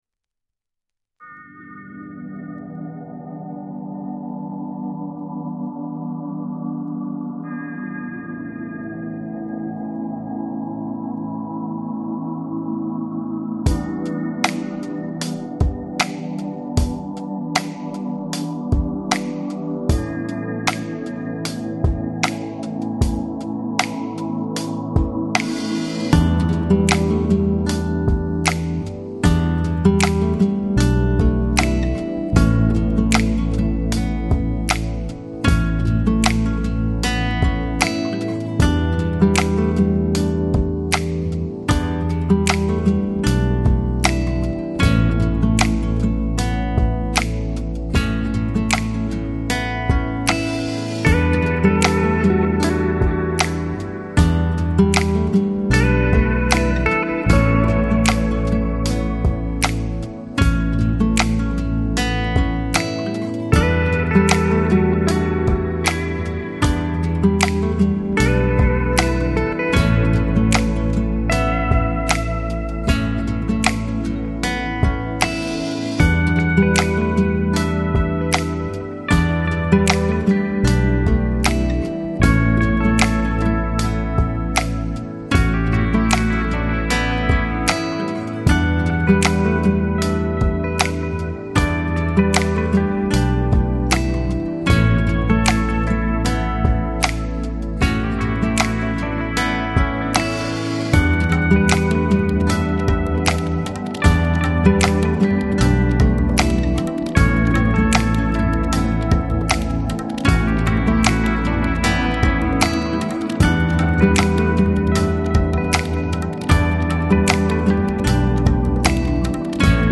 Chill Out, Smooth Jazz, Downtempo